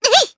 Rehartna-Vox_Jump_jp_b.wav